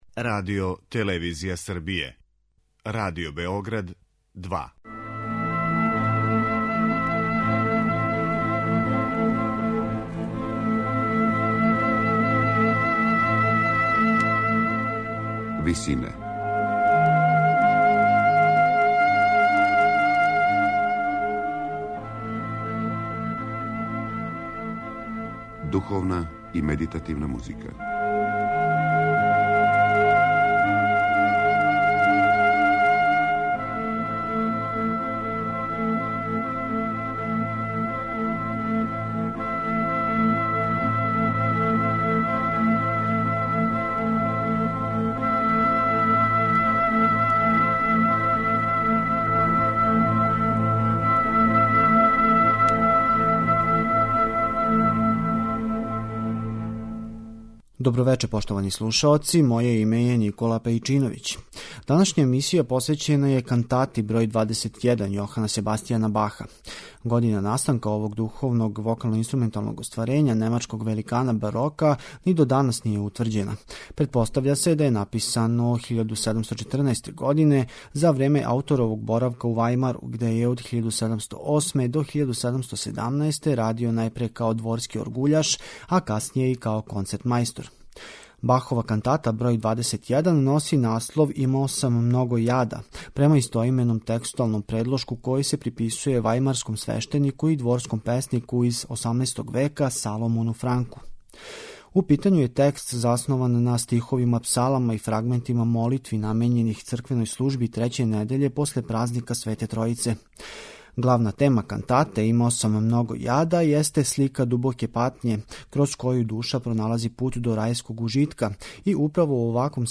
вокално-инструментално дело
медитативне и духовне композиције